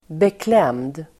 Ladda ner uttalet
Uttal: [bekl'em:d]